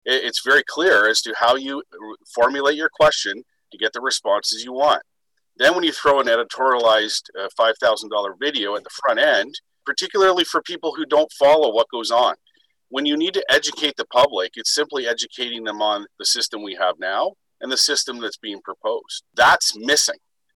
During Monday’s city council meeting, a handful of councillors including Councillor Paul Carr, said they had heard from constituents about the video and survey being one-sided.